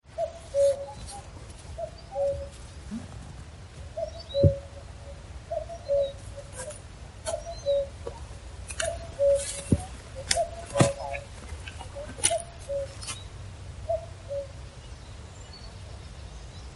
Short walk round Ardinning this morning, warm, thinks about being muggy if not for the breeze. Cuckoos calling, cuckoo & bluebells around.
cuckoo-64-audacity-edit.mp3